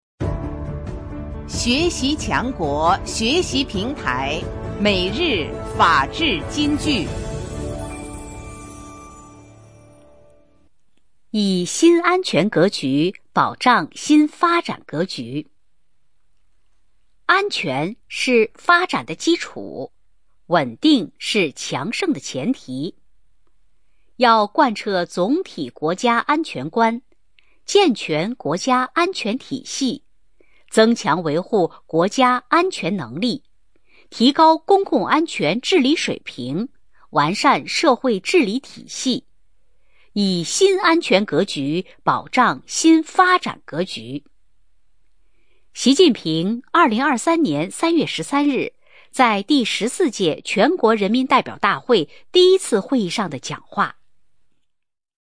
每日法治金句（朗读版）|以新安全格局保障新发展格局 _ 创建模范机关 _ 福建省民政厅